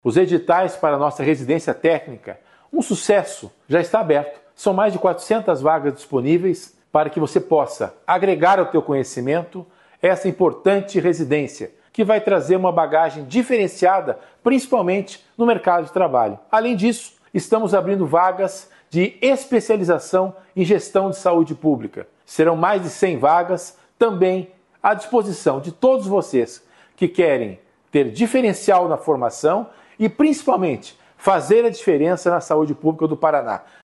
Sonora do secretário da Saúde em exercício, César Neves, sobre a Residência Técnica e Gestão em Saúde